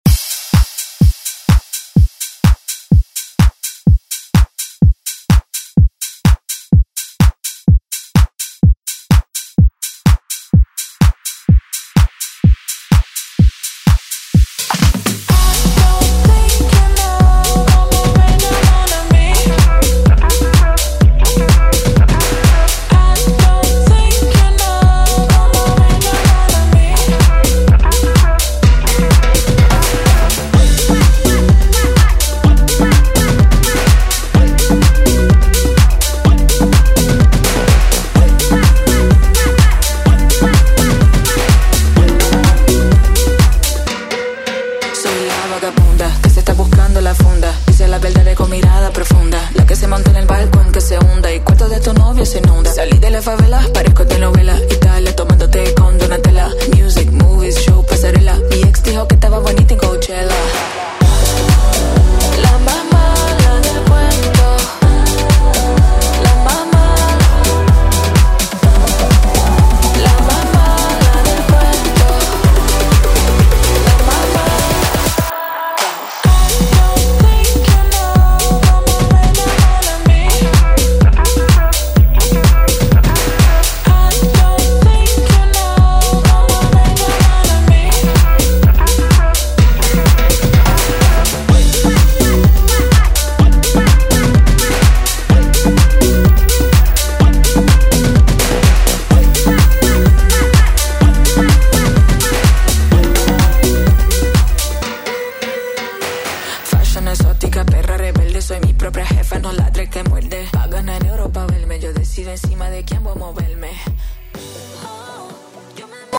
Classic Rock Music
Extended ReDrum Clean 116 bpm